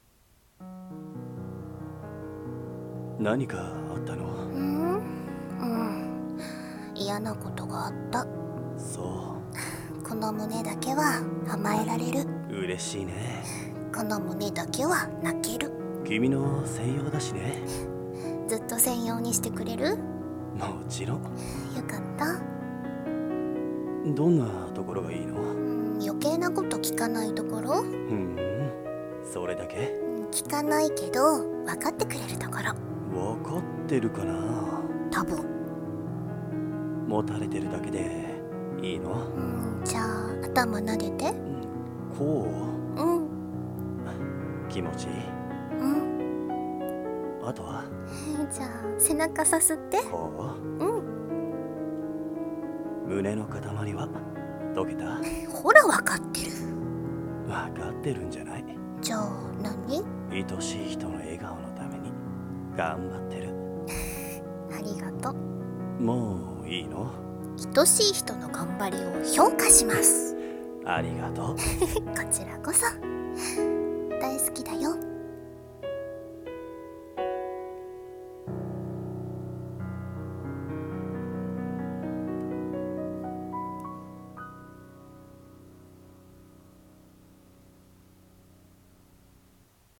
【声劇】手あて